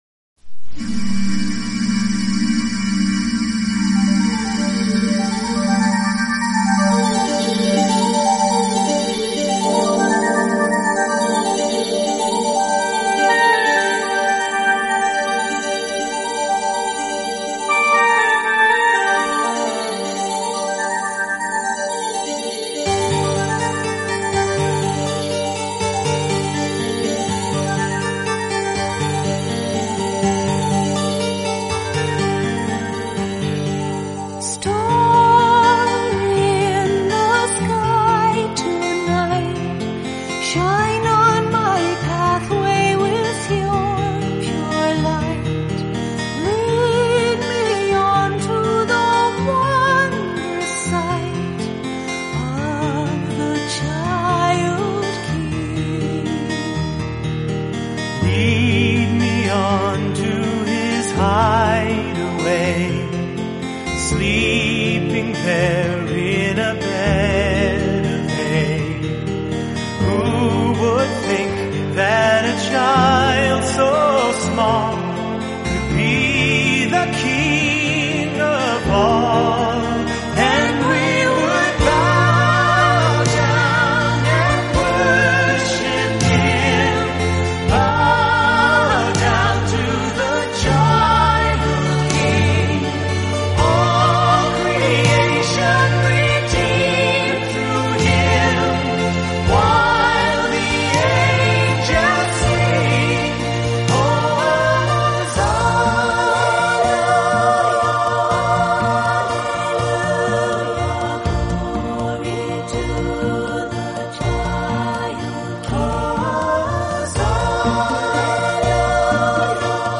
the harmonic group